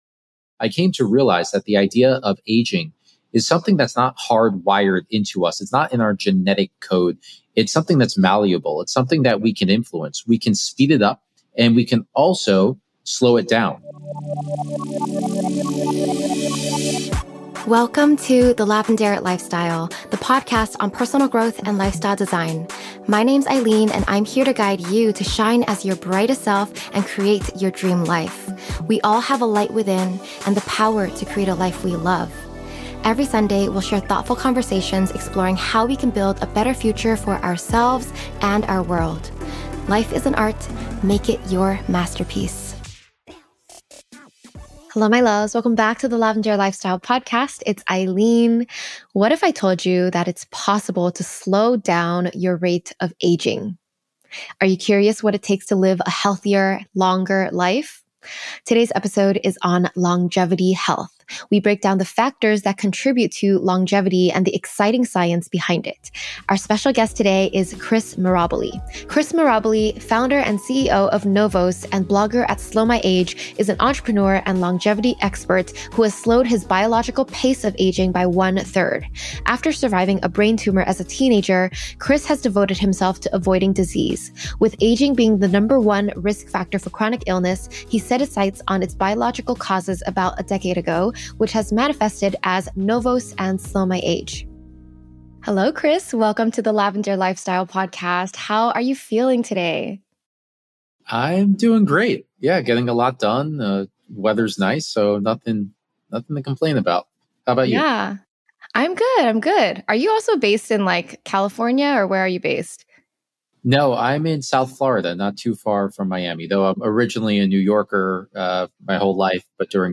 📹The video version of this interview is available on YouTube: Episode 260